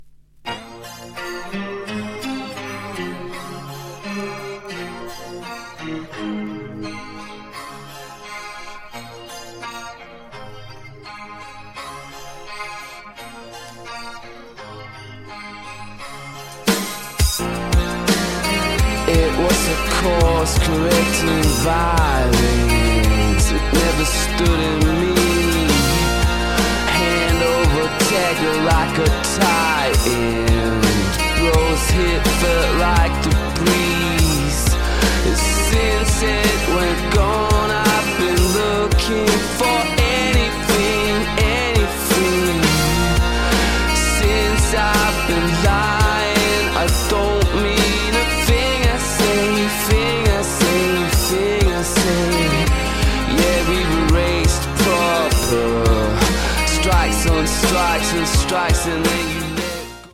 New Release Indie Rock New Wave / Rock